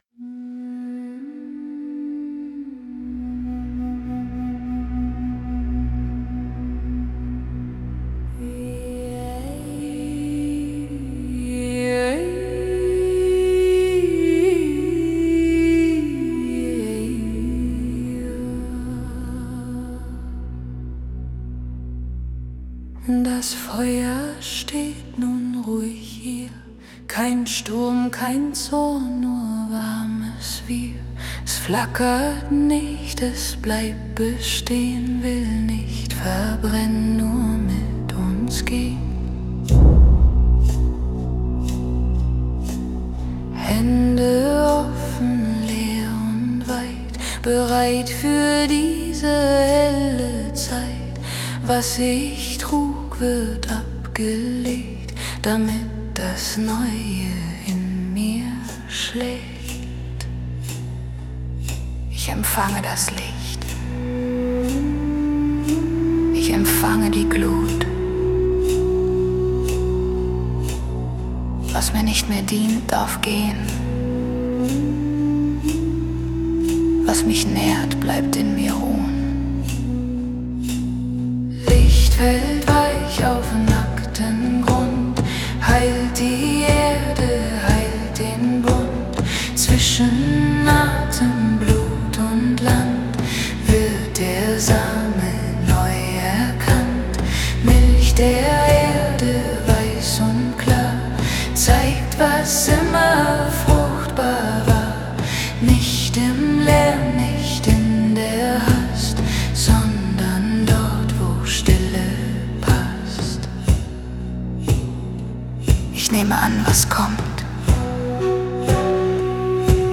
Musikalisch ist es tragend, ruhig und kraftvoll zugleich.